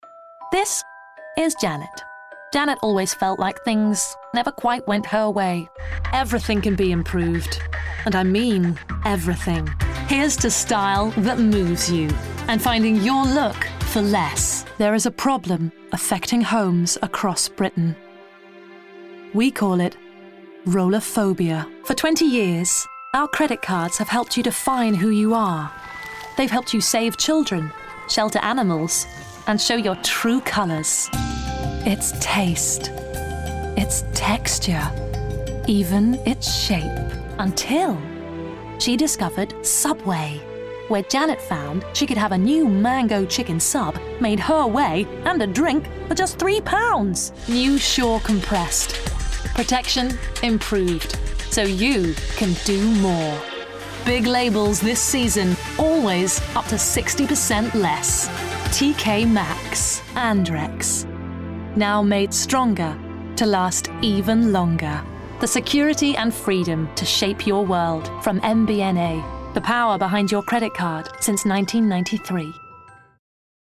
• Commercial Reel
With a plethora of voices and accents, she has worked extensively in audio drama, voicing many creations for Big Finish Productions, Audio books and recently Radio 4’s News Jack.